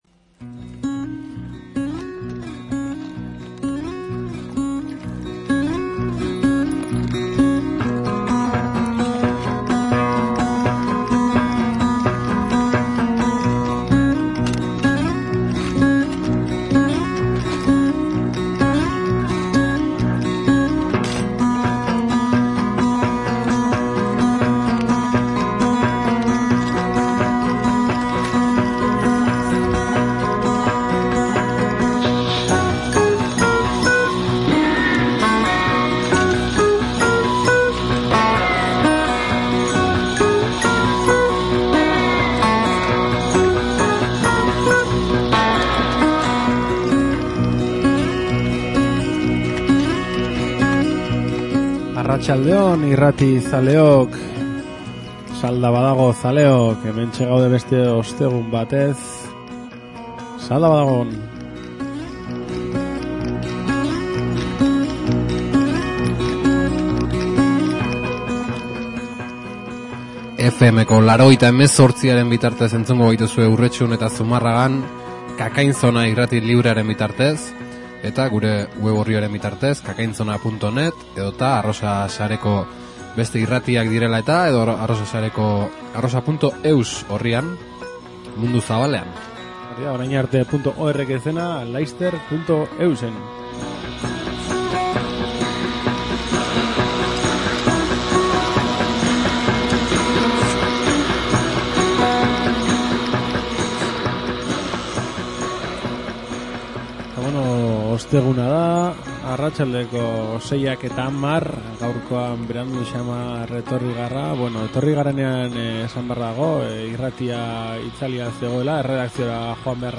Salda Badago: Petra taldeari elkarrizketa
Gaurkoan Salda Badagon Petra taldeari eginiko elkarrizketa entzuteko aukera izan dugu, Urretxu-Zumarragako Gaztetxean eman zuten zuzenekoaren harira.